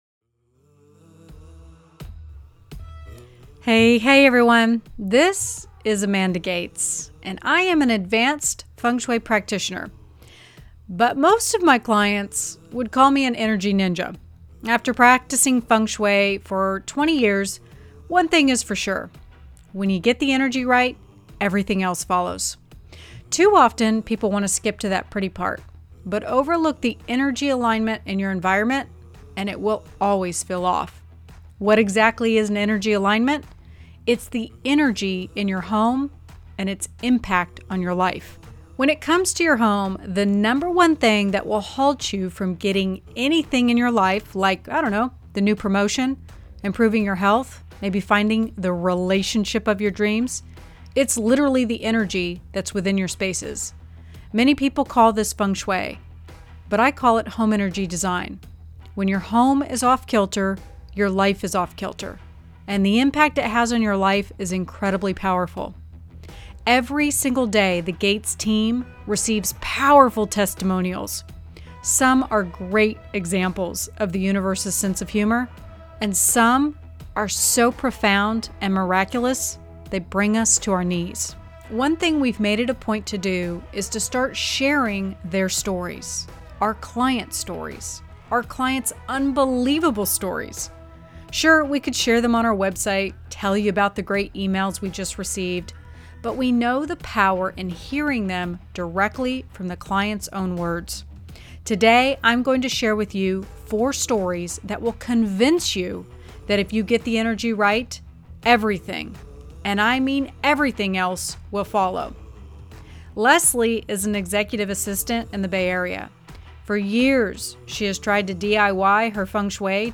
Listen to what others have said about their floor plan reading
Testimonials.m4a